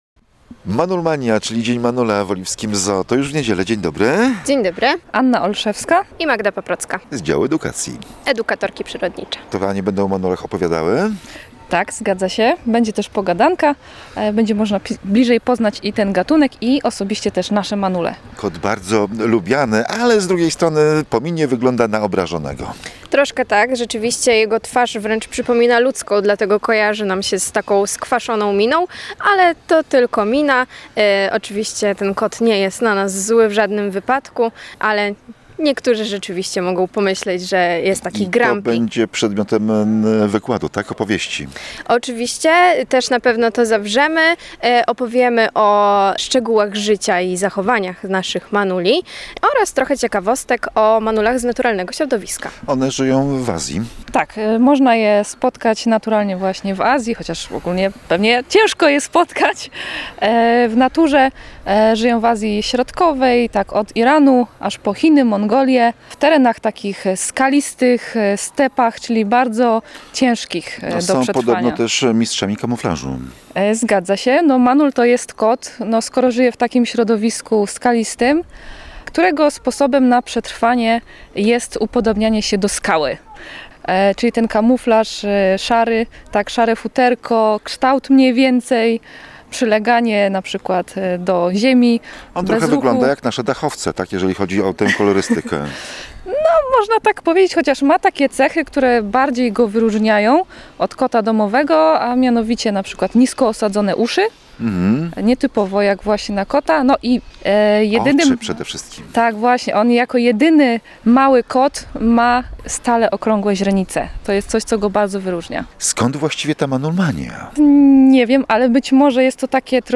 W audycji o manulach opowiadają edukatorki przyrodnicze